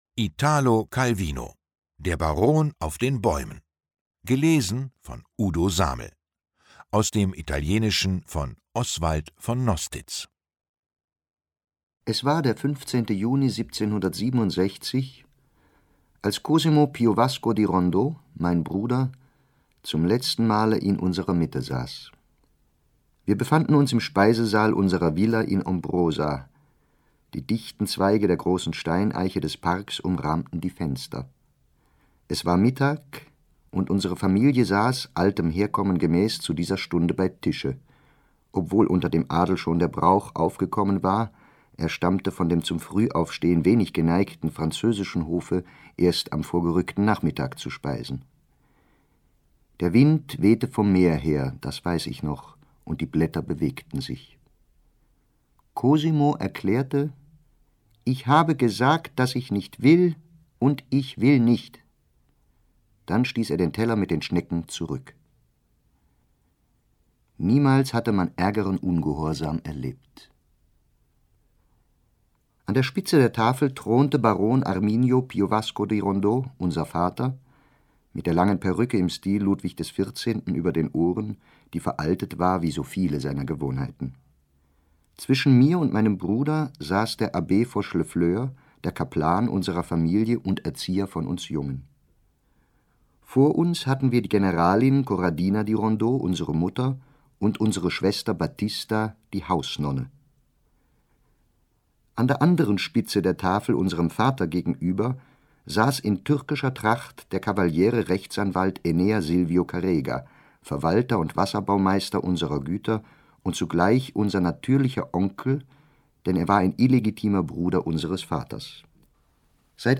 Ungekürzte Lesung mit Udo Samel (1 mp3-CD)
Udo Samel (Sprecher)